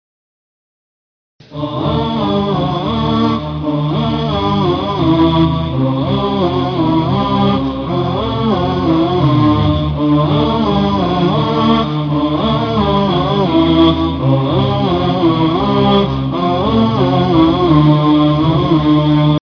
طلب ((آهــــــات)) ..<عاجل>